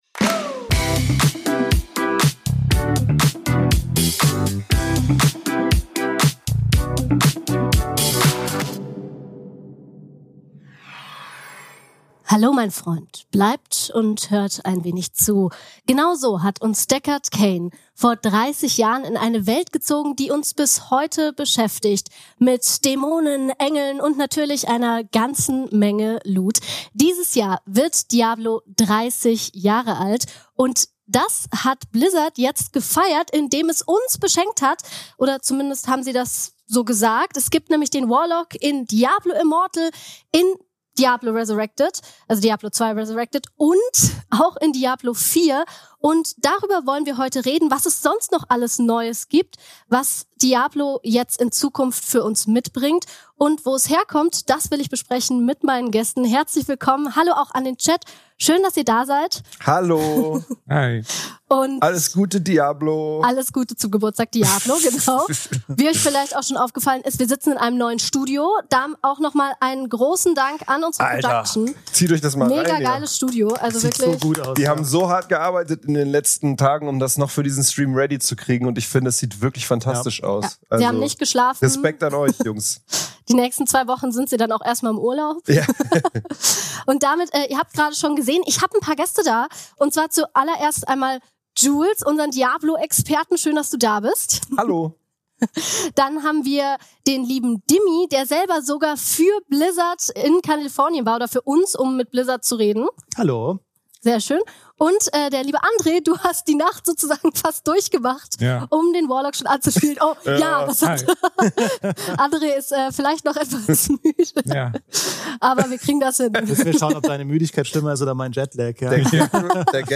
GameStar Talk